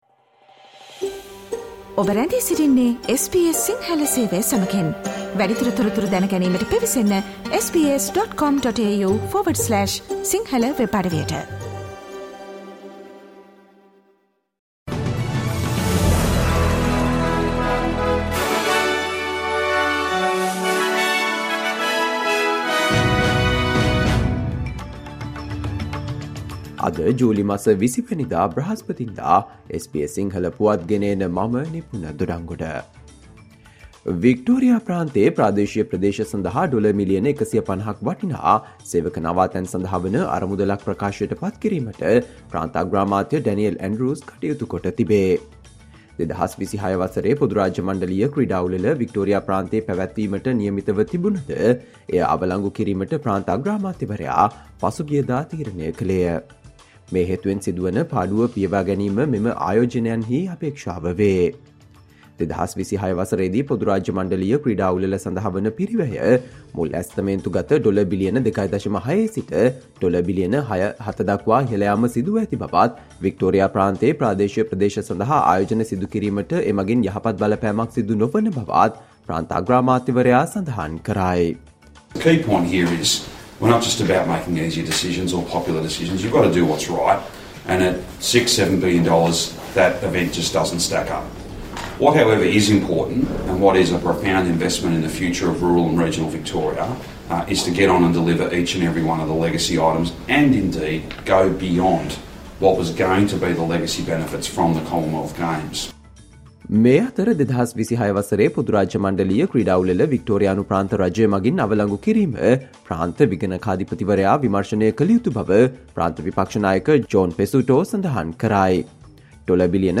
Australia news in Sinhala, foreign and sports news in brief - listen, today - Thursday 20 July 2023 SBS Radio News